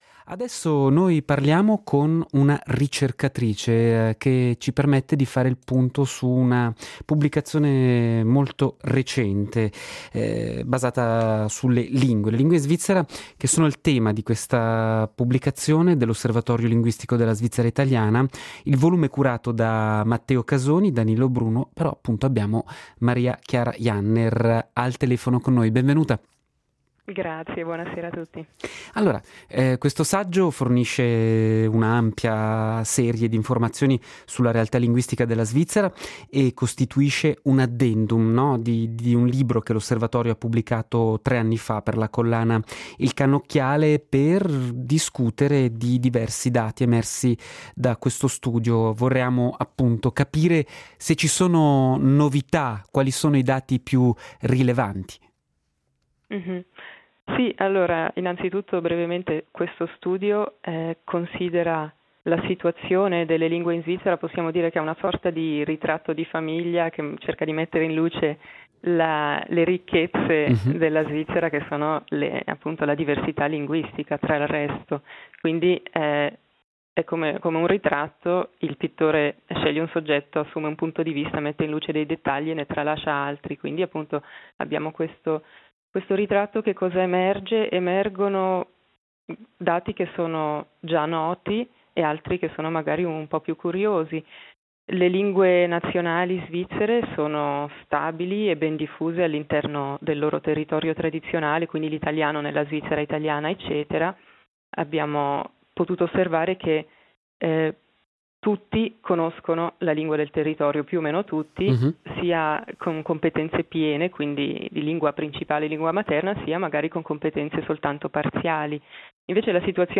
“Cannocchiale” puntato sulle lingue parlate nel nostro paese. Intervista